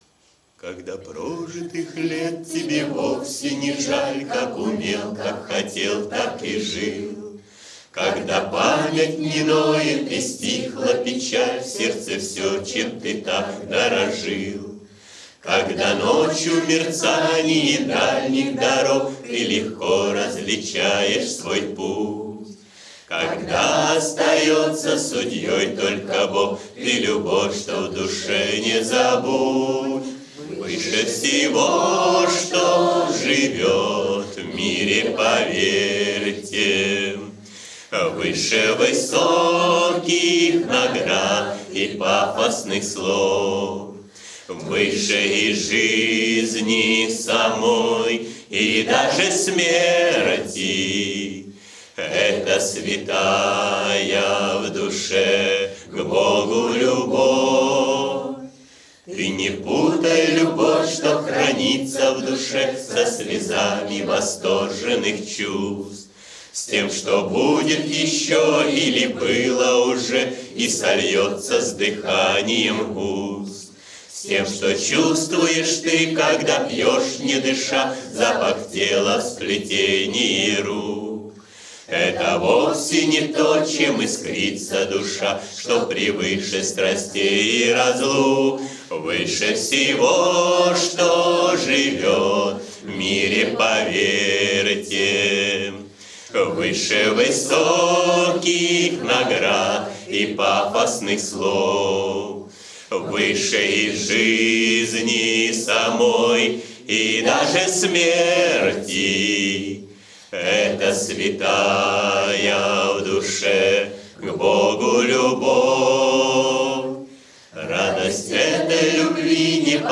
кавер-версия
акапелла